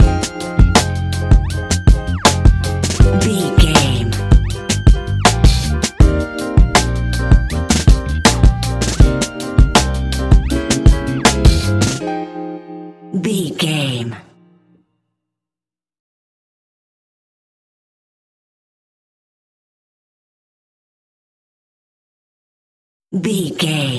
Ionian/Major
F♯
laid back
sparse
new age
chilled electronica
ambient